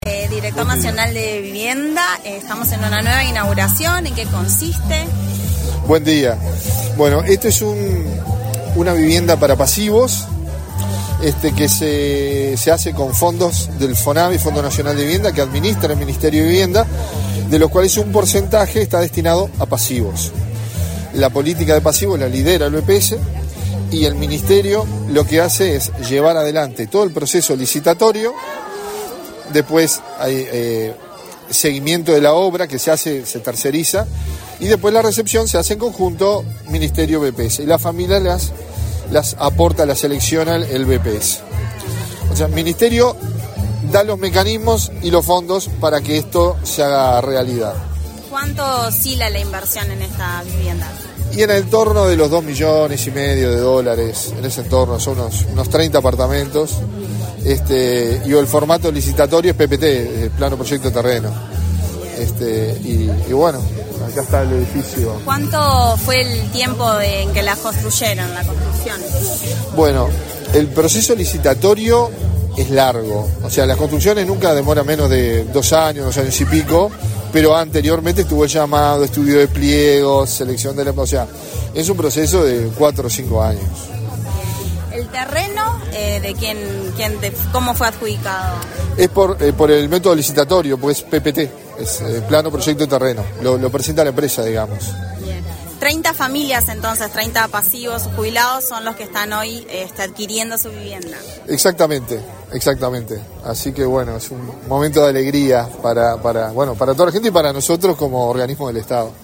Entrevista al director nacional de Vivienda, Eduardo González
Tras el evento, el director nacional de Vivienda, Eduardo González, realizó declaraciones a Comunicación Presidencial.